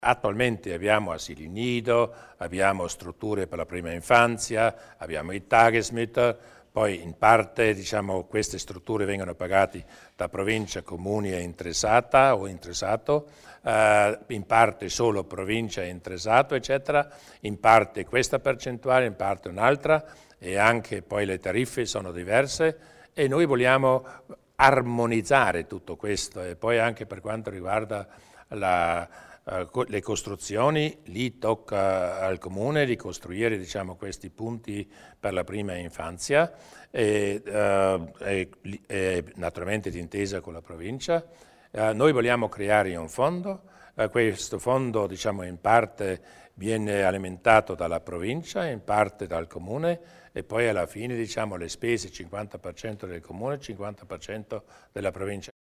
Il Presidente Durnwalder illustra i dettagli della prima parte della legge sulla famiglia